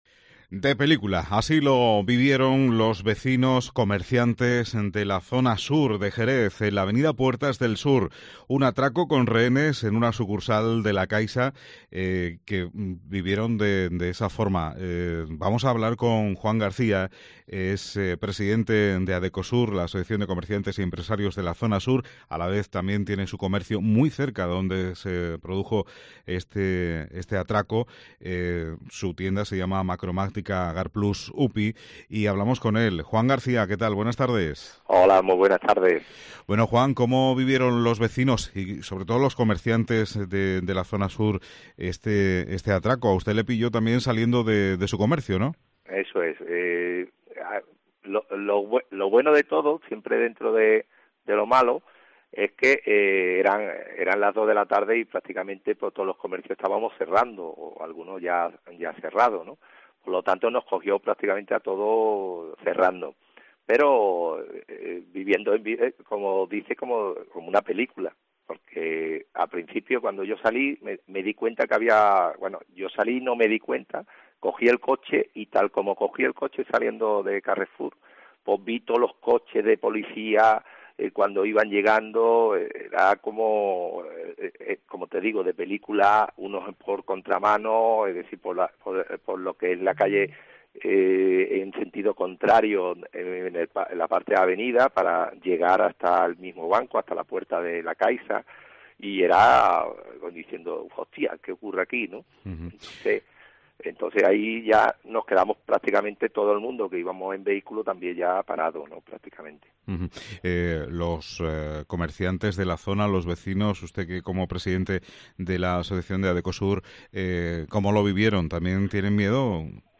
Testigos sobre el atraco a una sucursal de Jerez